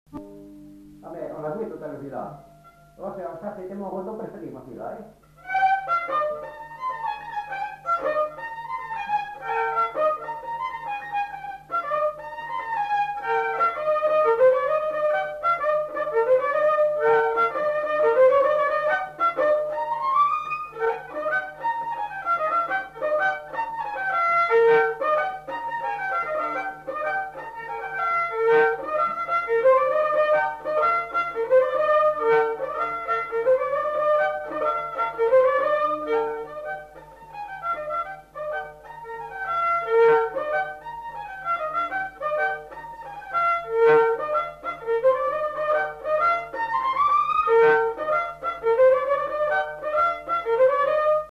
Genre : morceau instrumental
Instrument de musique : violon
Danse : rondeau